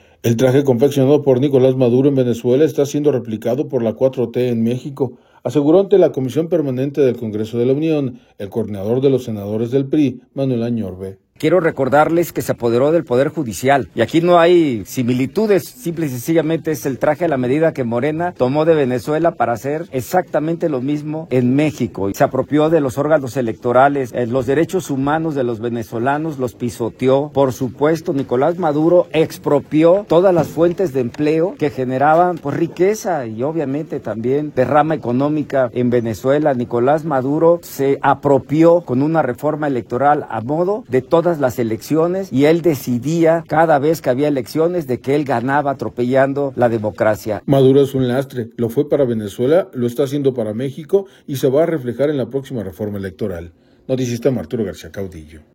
El traje confeccionado por Nicolás Maduro en Venezuela está siendo replicado por la 4T en México, aseguró ante la Comisión Permanente del Congreso de la Unión, el coordinador de los senadores del PRI, Manuel Añorve.